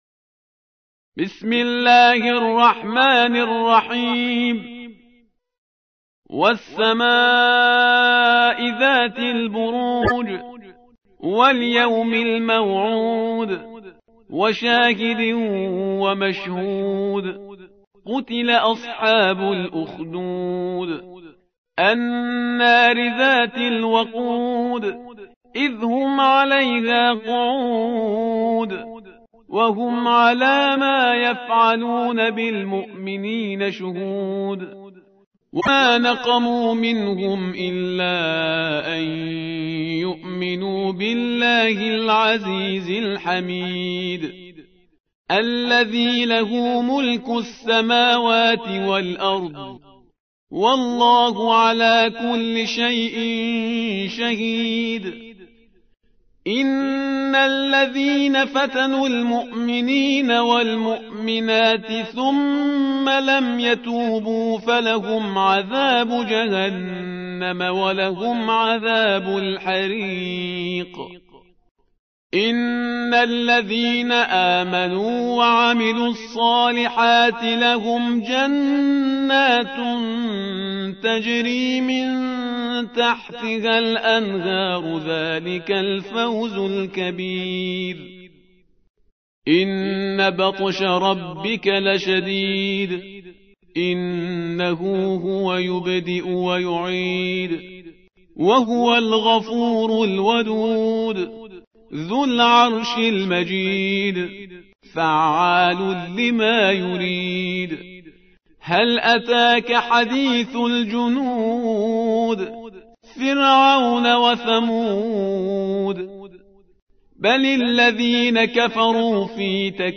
موقع يا حسين : القرآن الكريم 85.